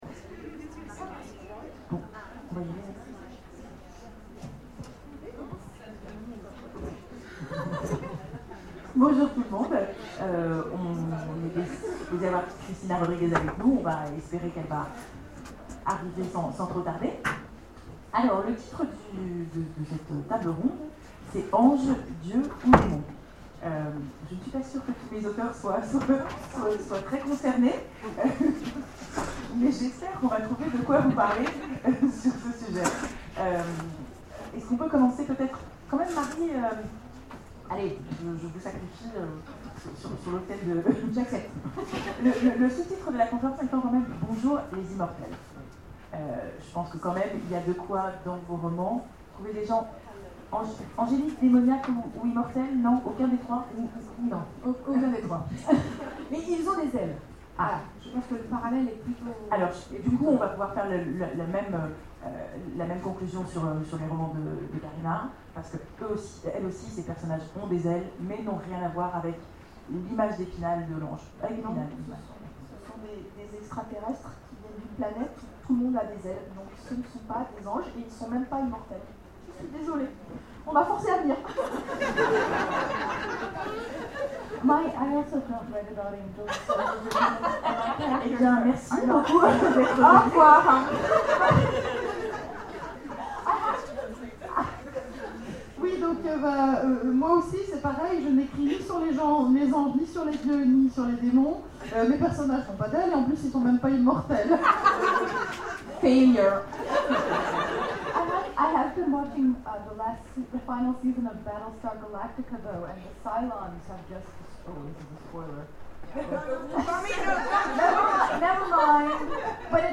Imaginales 2013 : Conférence Anges, dieux ou démons ?